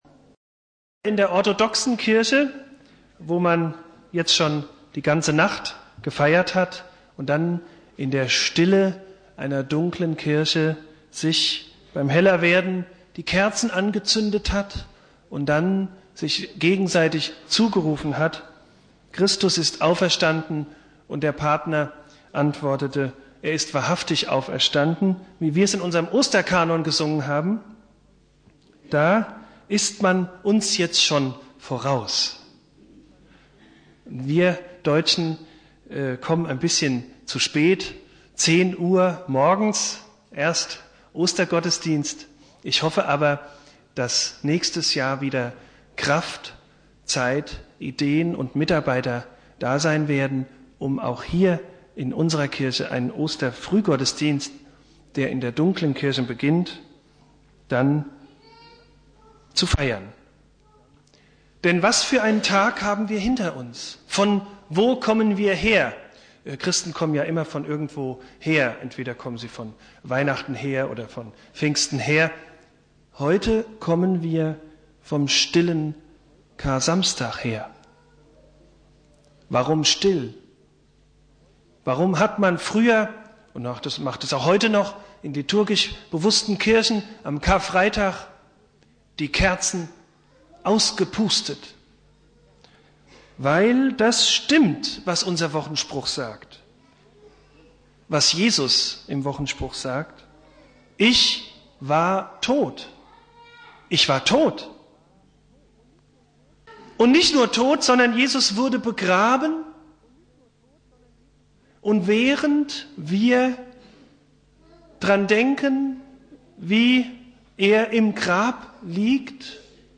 Predigt
Ostersonntag